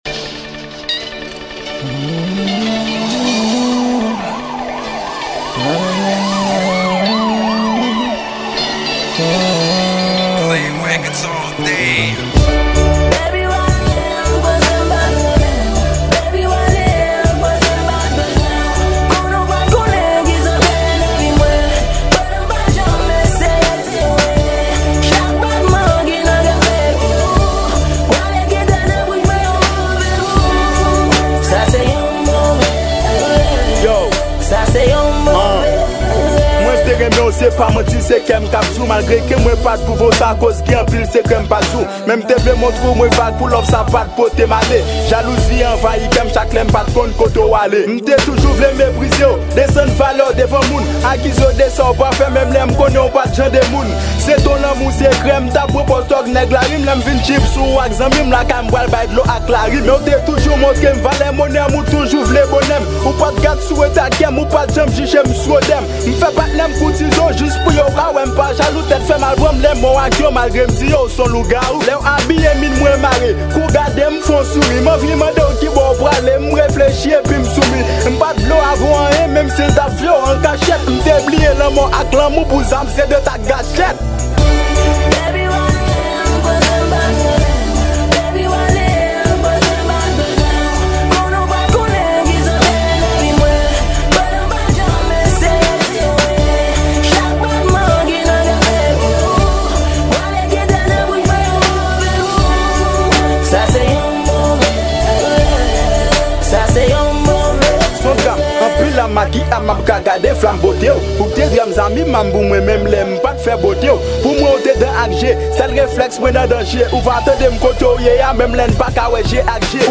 Genre: Hip-Hop.